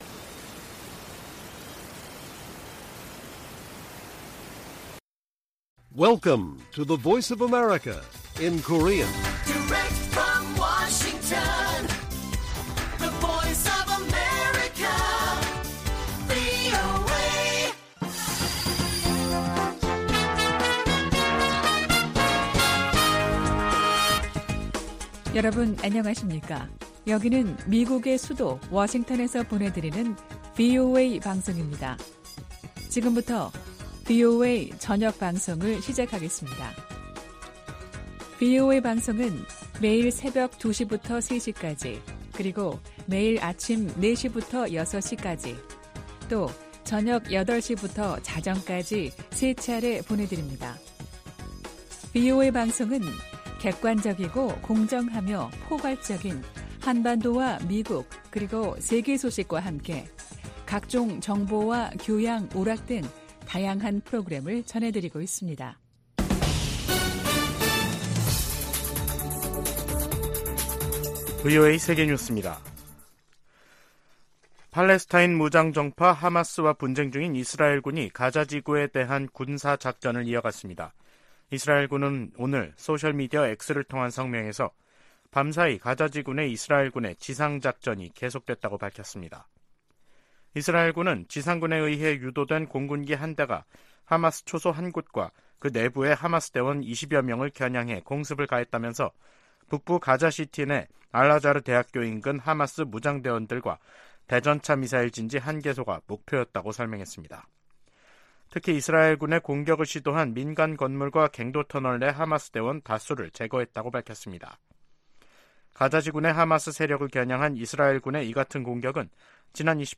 VOA 한국어 간판 뉴스 프로그램 '뉴스 투데이', 2023년 10월 30일 1부 방송입니다. 유엔총회 제1위원회가 북한의 핵무기와 대량살상무기 폐기를 촉구하는 내용이 포함된 결의안 30호를 통과시키고 본회의에 상정했습니다. 하마스가 이스라엘 공격에 북한제 대전차무기를 사용하고 있다고 중동문제 전문가가 말했습니다. 미 국무부는 북한과의 무기 거래를 부인한 러시아의 주장을 일축하고, 거래 사실을 계속 폭로할 것이라고 강조했습니다.